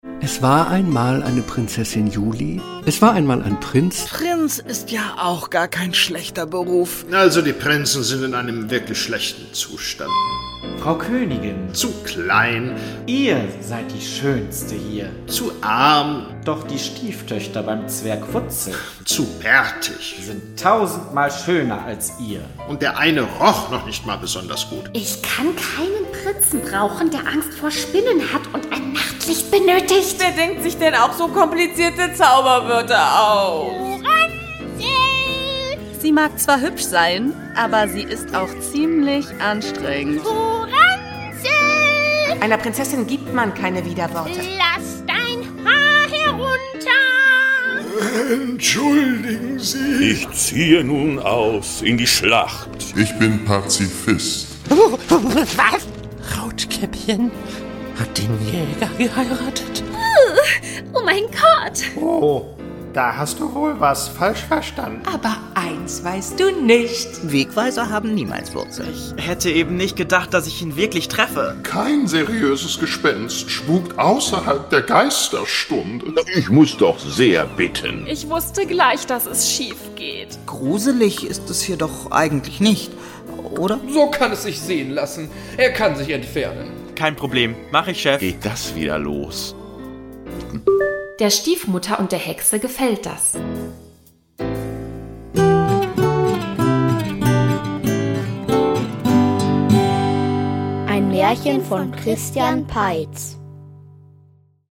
Eine Collage